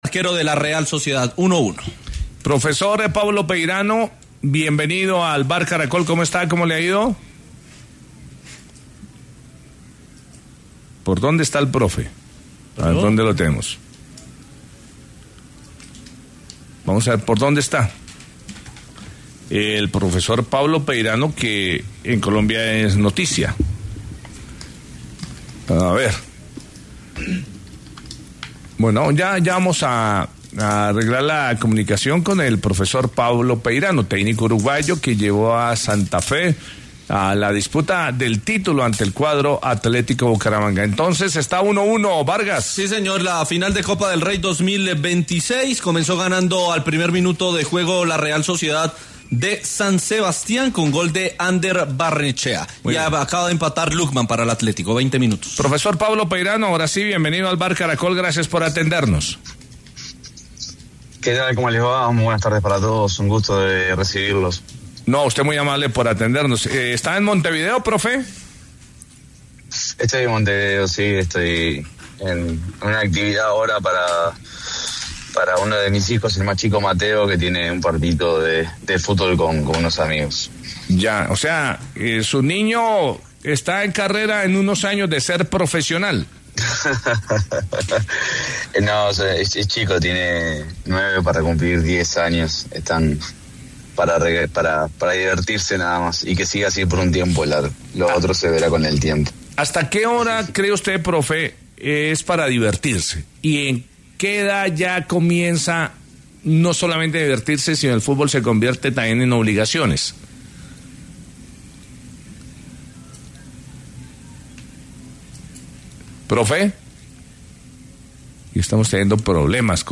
El entrenador uruguayo Pablo Peirano habló en los micrófonos de El VBar Caracol sobre su presente profesional y los acercamientos que ha tenido con varios equipos del fútbol colombiano, luego su salida de Nacional de Montevideo.